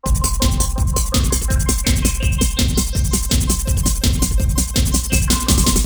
__BEEP POP 3.wav